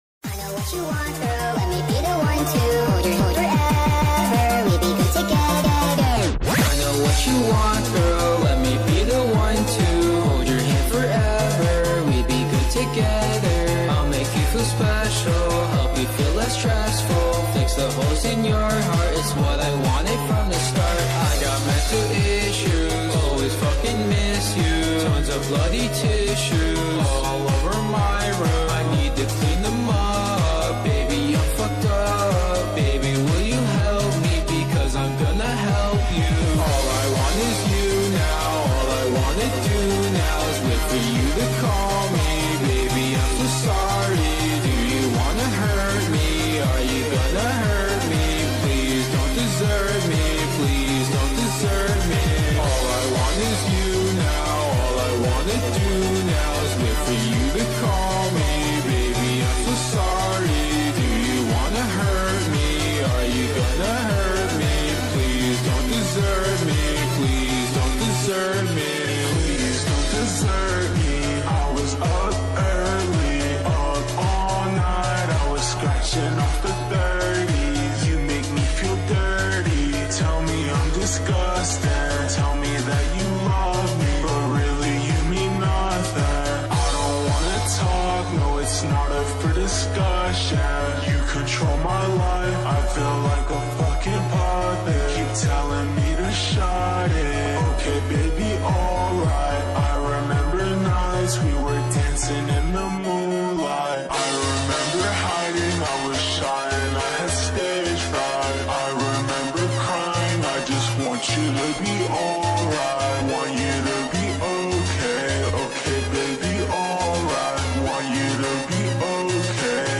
Evolution of Xiaomi (Mi) series sound effects free download